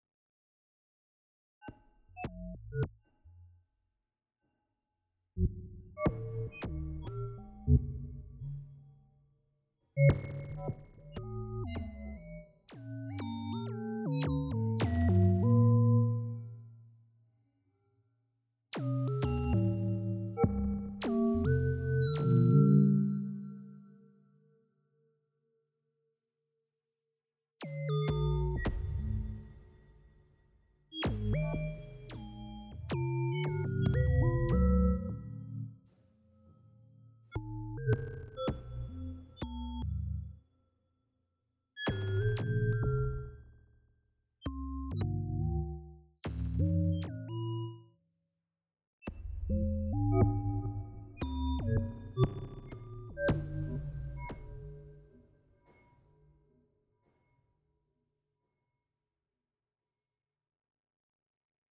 Having fun with , and additive synthesis.